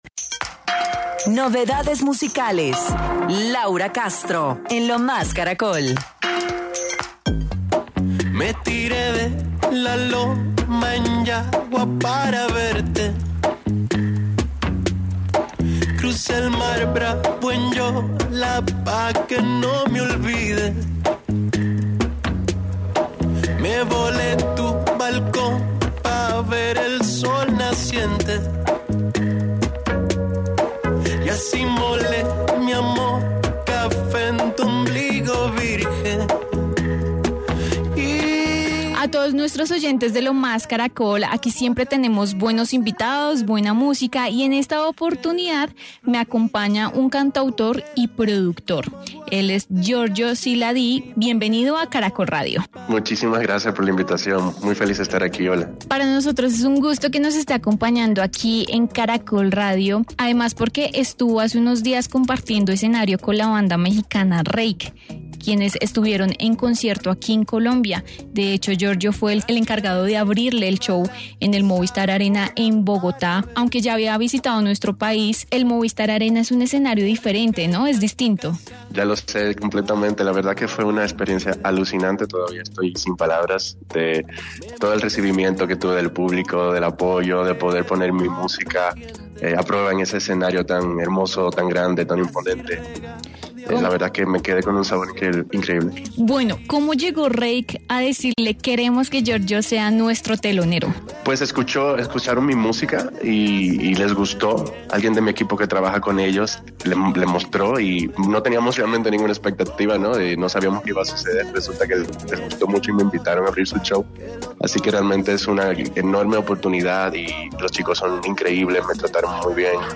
El cantautor dominicano, dio detalles de su conexión con la agrupación mexicana y su encanto por la ciudad